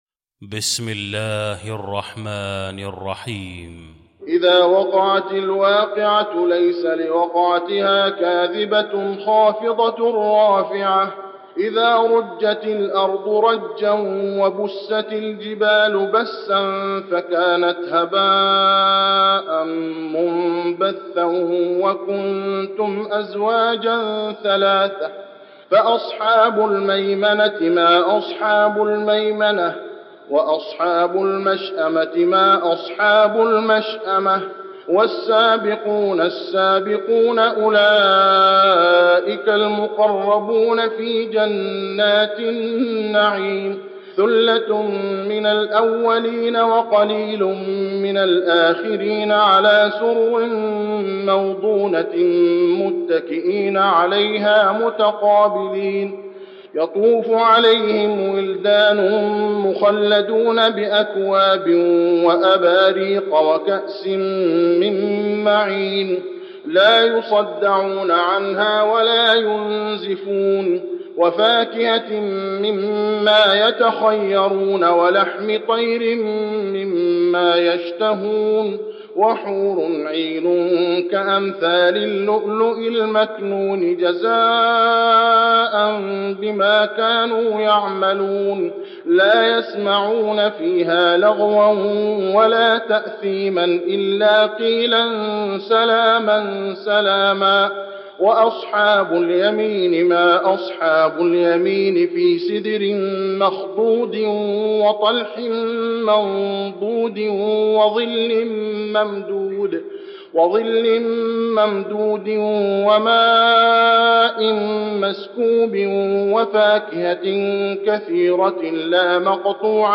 المكان: المسجد النبوي الواقعة